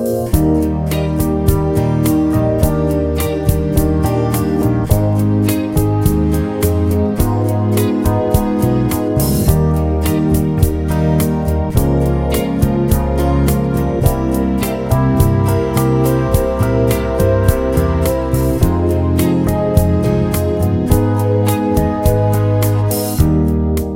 Slowed Down Pop (1960s) 3:42 Buy £1.50